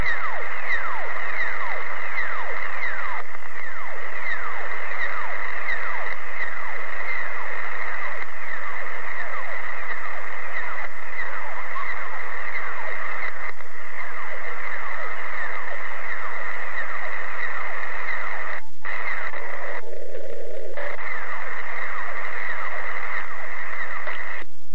Testy byly konány dne 23.8.2004 asi kolem 17. hodiny našeho času v pásmu 80m.
Je to slyšet moc..HI.